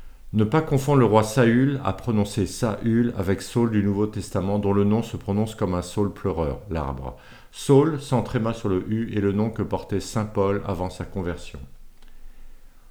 Ne pas confondre le roi Saül - à prononcer "sa-hul" - avec Saul du Nouveau Testament, dont le nom se prononce comme un saule pleureur (l'arbre).